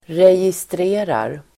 Uttal: [rejistr'e:rar]